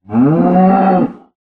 cow1.ogg